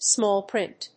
アクセントsmáll prínt